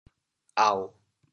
How to say the words 区 in Teochew？